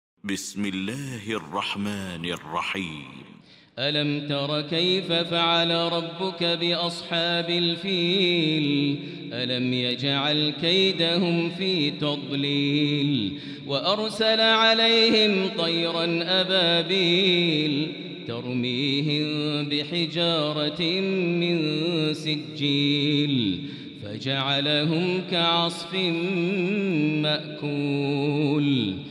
المكان: المسجد الحرام الشيخ: فضيلة الشيخ ماهر المعيقلي فضيلة الشيخ ماهر المعيقلي الفيل